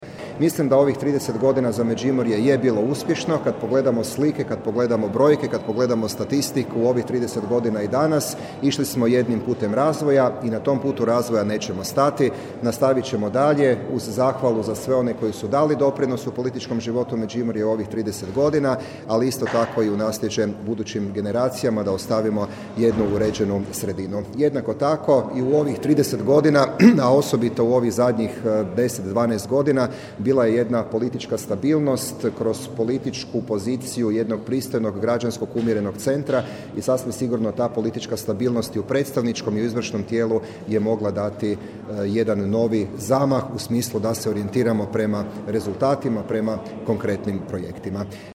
Međimurska županija, 12. sjednica svečani dio u povodu 30. godišnjice konstituiranja prvog saziva Skupštine Međimurske županije, 13.4.2023.
Međimurci su se za županiji morali izboriti peticijom, podsjetio je župan Matija Posavec, dodajući da je potom njezin ustroj donio prekretnicu u društvenom, gospodarskom, političko-kulturnom životu, a razvoj tu neće stati, poručio je.